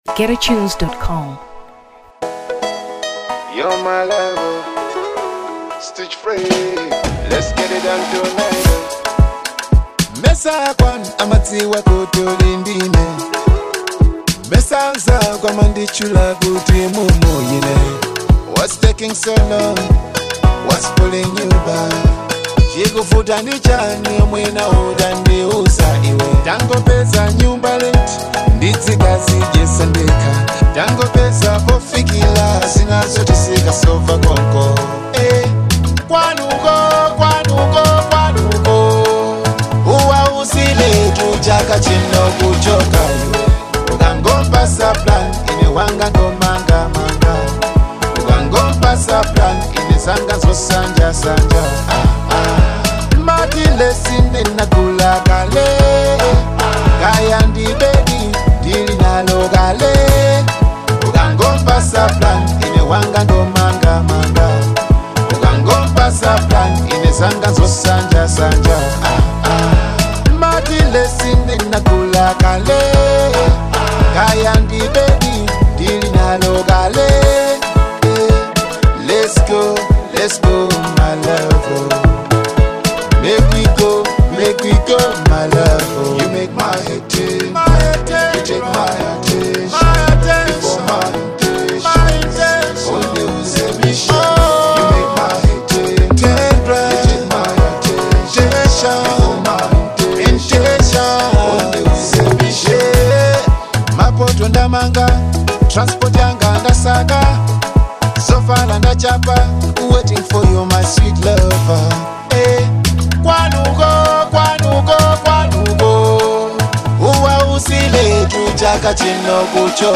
Afrobeats 2023 Malawi